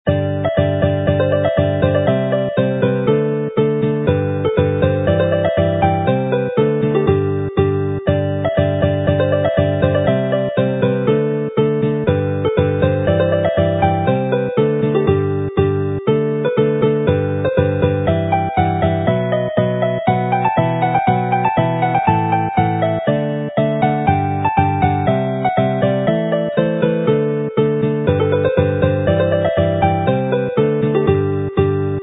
mp3 file as a march, fast with chords